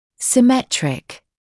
[sɪ’metrɪk][си’мэтрик]симметричный